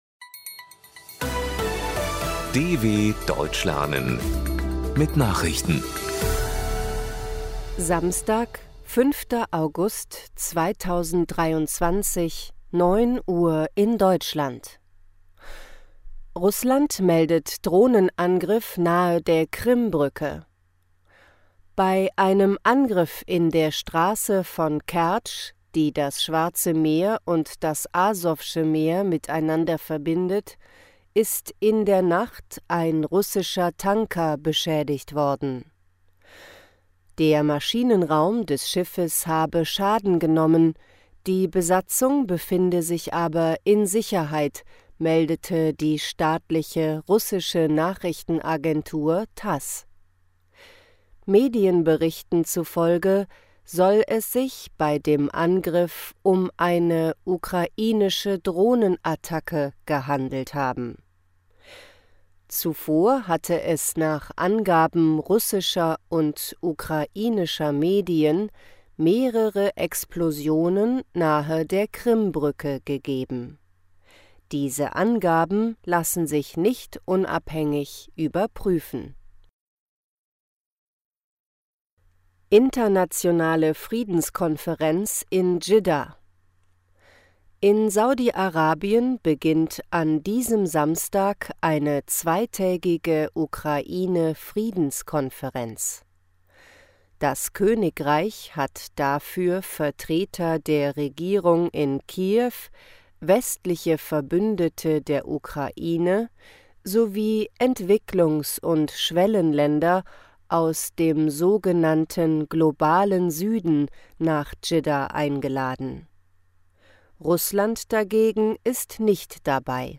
05.08.2023 – Langsam Gesprochene Nachrichten
Trainiere dein Hörverstehen mit den Nachrichten der Deutschen Welle von Samstag – als Text und als verständlich gesprochene Audio-Datei.